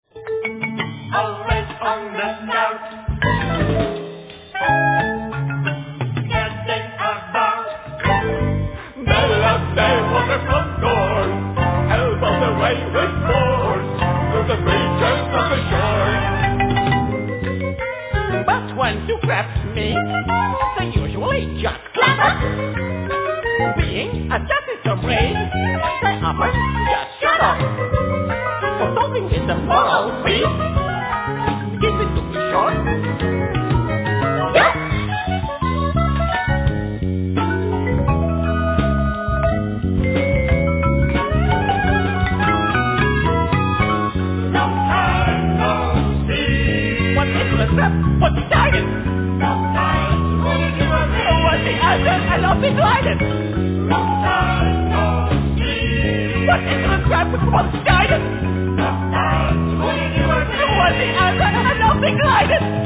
Vocals
Soprano+Tenor Sax
Yamaha Grantouch(Piano)
Marimba,Korg 01W,Programming & conducting
5-string e.bass
Drums